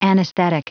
Prononciation du mot anesthetic en anglais (fichier audio)